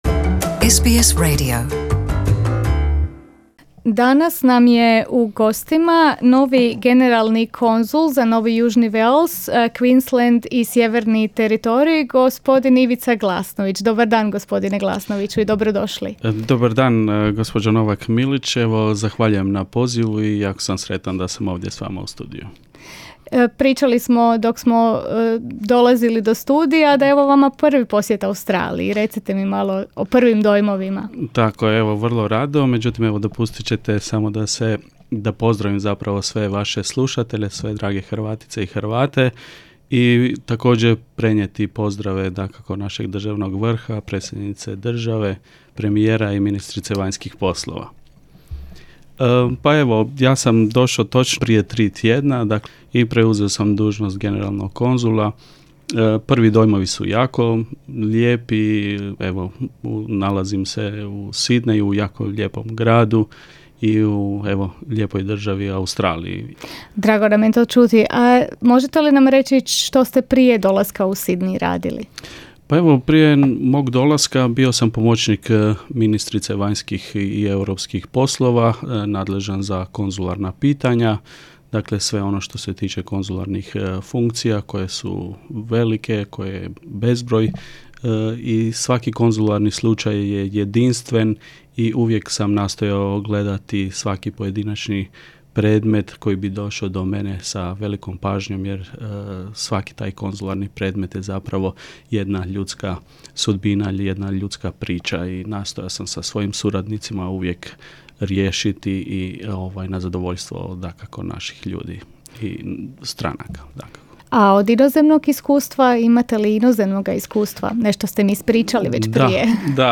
Interview with a new Consul General of the Republic of Croatia to New South Wales, Queensland and Northern Territory, Mr Ivica Glasnović.